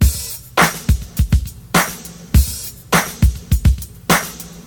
103 Bpm Drum Loop Sample C# Key.wav
Free drum loop - kick tuned to the C# note. Loudest frequency: 2175Hz
103-bpm-drum-loop-sample-c-sharp-key-KtA.ogg